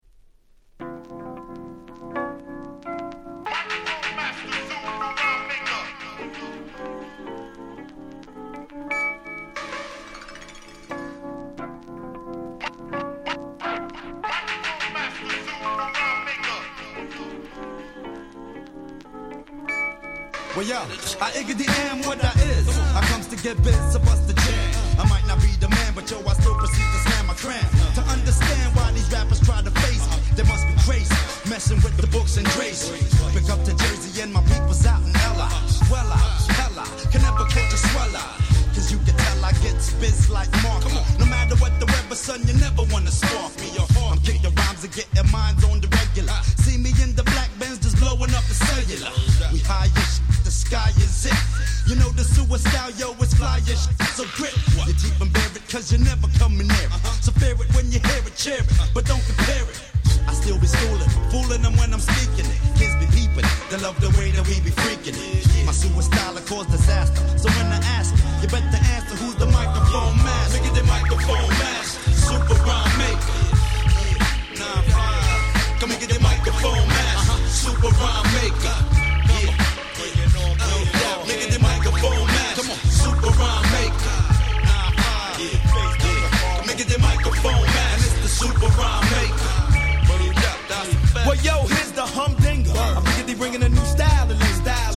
95' Smash Hit Hip Hop !!